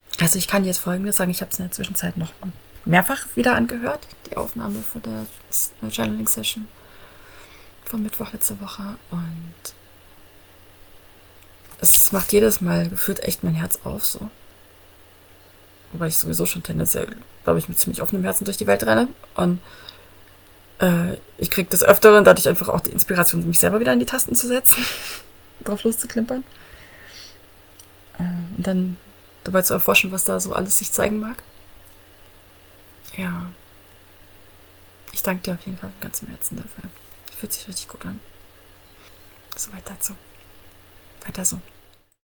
Find a quick introduction and an example of the feedback round (in english) after an online session - with the sessions' music in the background: